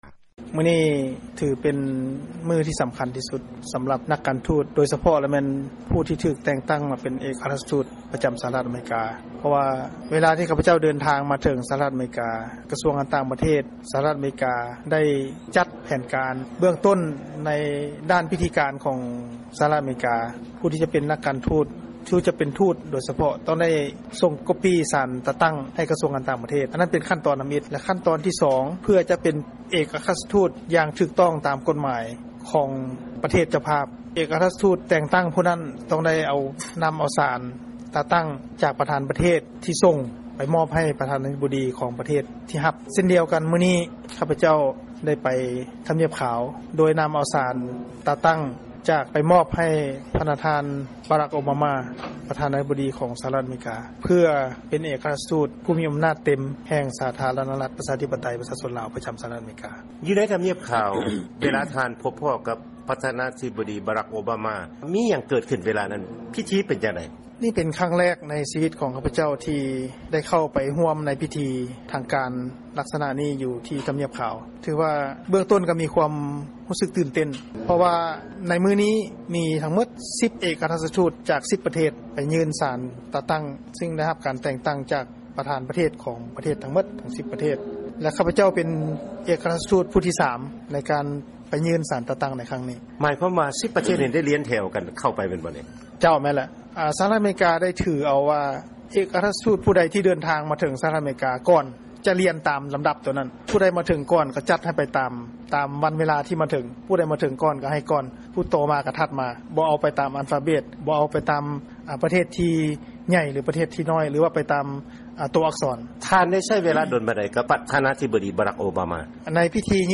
ຟັງການສຳພາດ ພະນະທ່ານ ເອກອັກຄະລັດຖະທູດ ໄມ ໄຊຍະວົງສ໌ ຈາກ ສປປ ລາວ 2